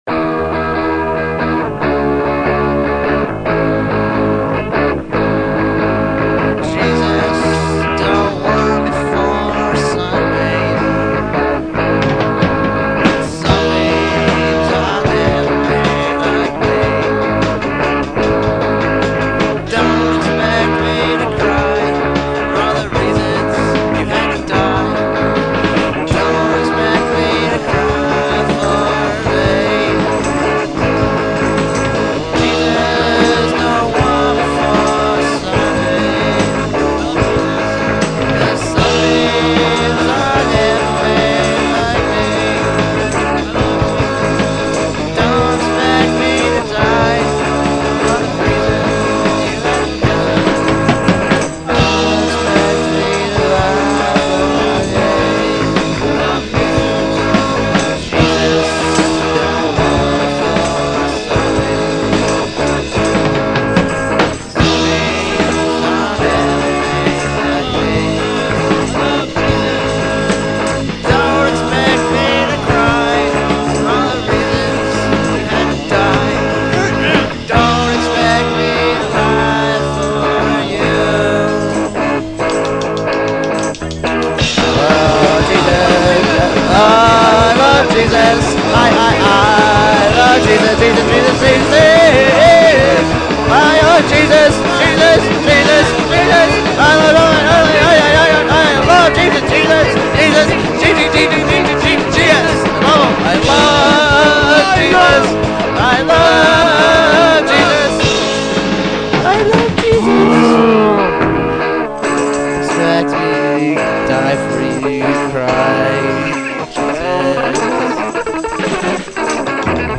Classic 1990s Lehigh Valley punk
punk rock See all items with this value
cassette